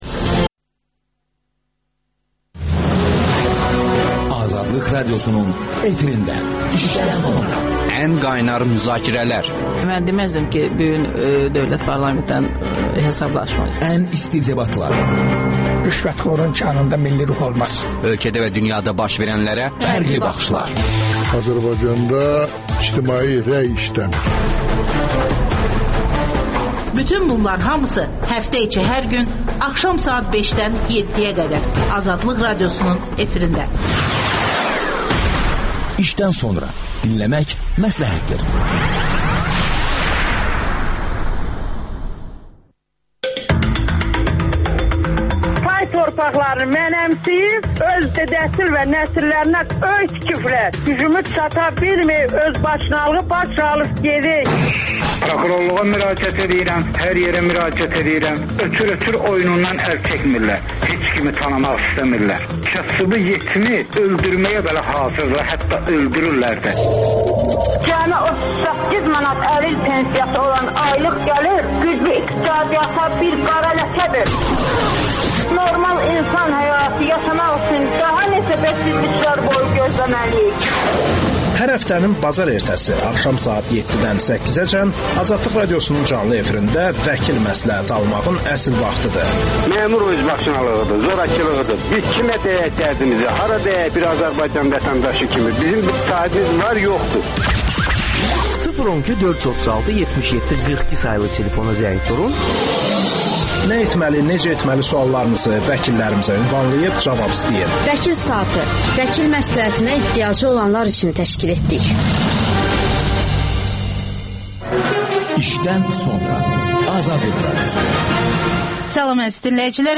Siyasətçi, hüquqşünas Erkin Qədirli